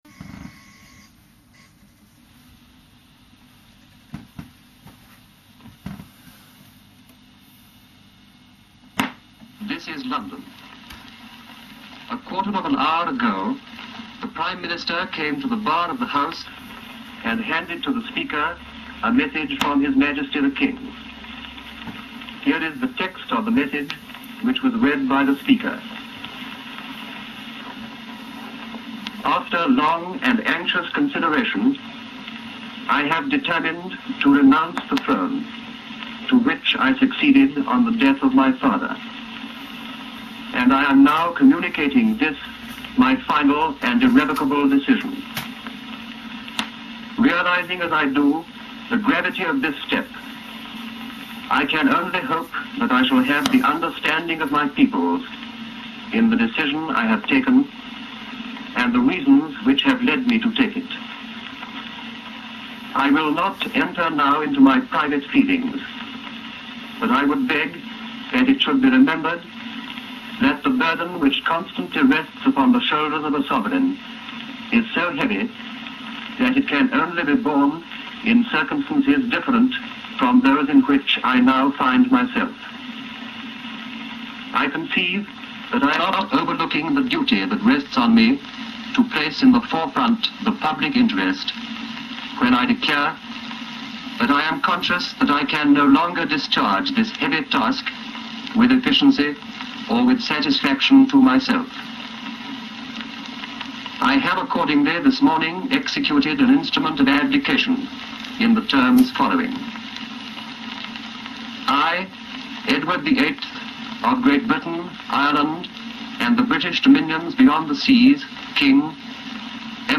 Listen here to Alvar Lidell announcing the Abdication of King Edward VIII in 1936
Please note: there is quite a long pause (10 seconds) at the beginning of this clip
alvar-Liddell-radio-broadcast-on-the-abdication-of-Edward-8th.mp3